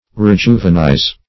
Rejuvenize \Re*ju`ve*nize\ (r?-j?"v?-n?z)
rejuvenize.mp3